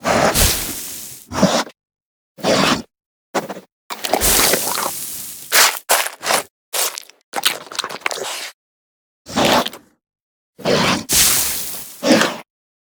File:Sfx creature snowstalkerbaby chill 01.ogg - Subnautica Wiki
Sfx_creature_snowstalkerbaby_chill_01.ogg